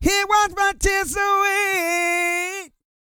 E-GOSPEL 234.wav